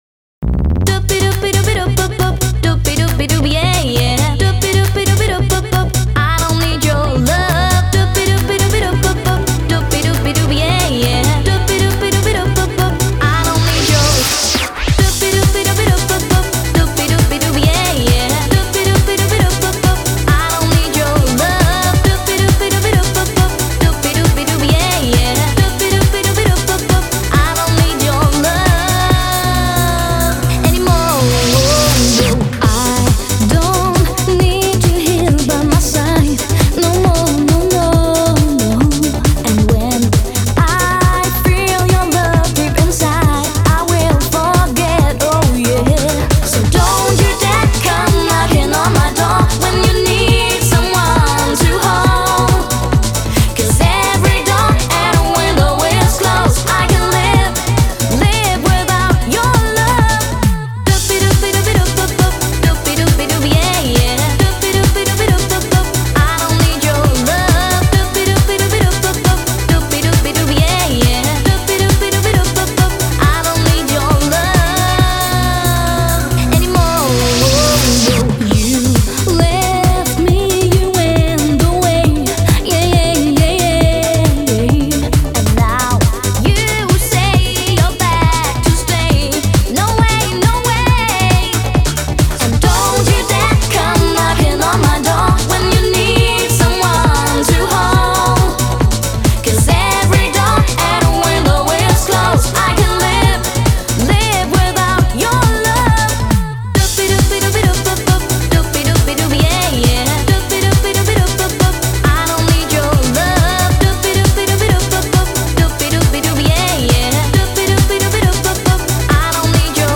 это энергичная поп-песня в стиле Eurodance